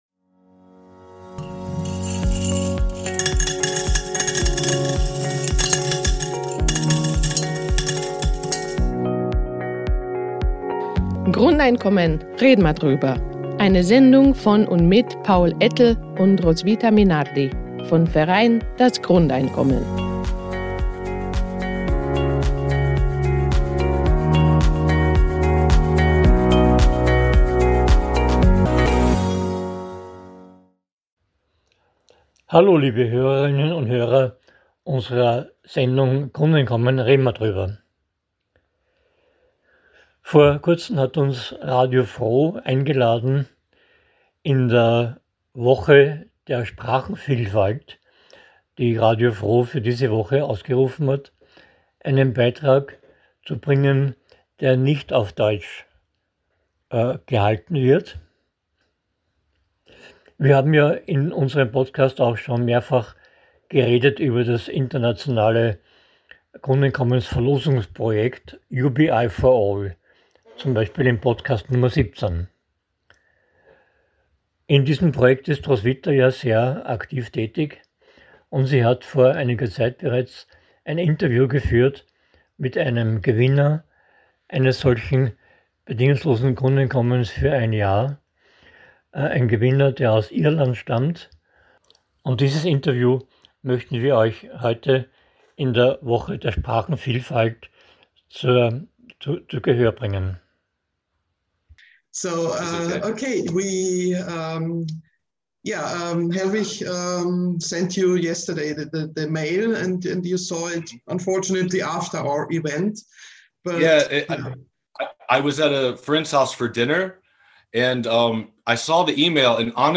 #70: Interview with an Irish UBI4ALL-Winner ~ Grundeinkommen - Red'n ma drüber! Podcast